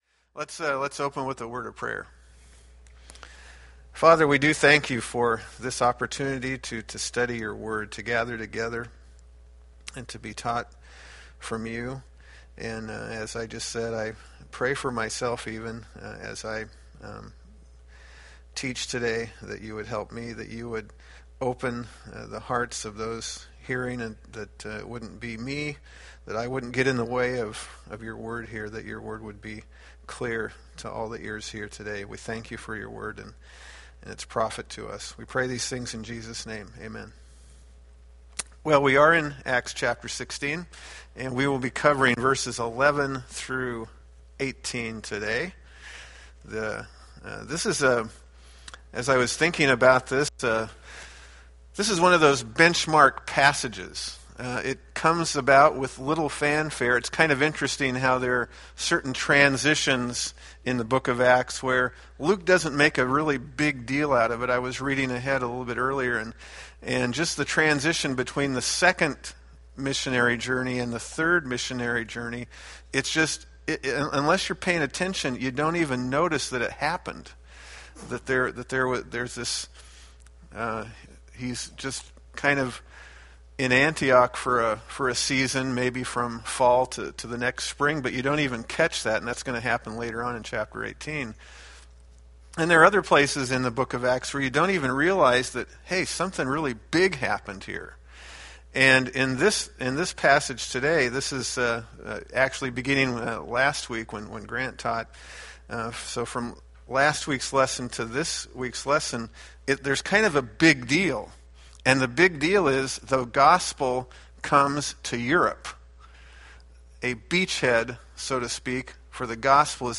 Acts Class - Week 34